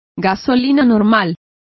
Complete with pronunciation of the translation of regulars.